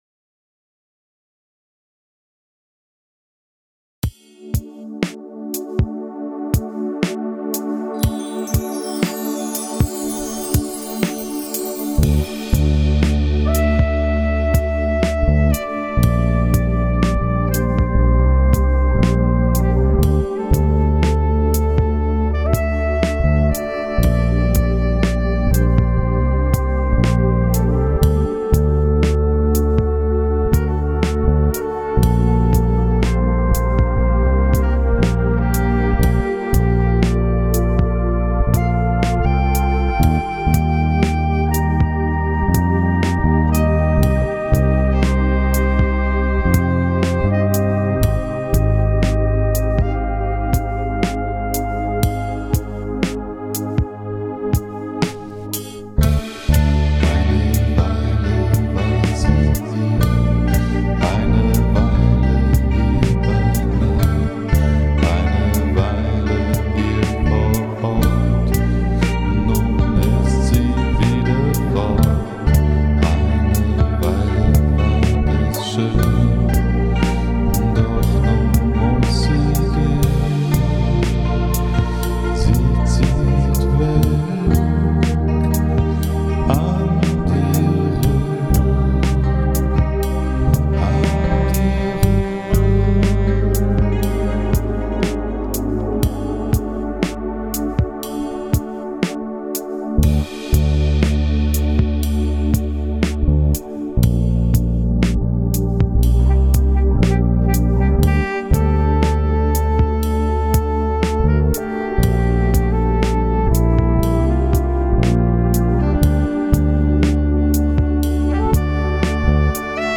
n abend, würd mich freun, wenn der ein oder andre reinhört.. erstmal interessiert mich, ob der bass so klingt, wie er soll.. satt und breit ohne zu brummen oder so..
(das sax im letzten part is eigentlich nur platzhalter..)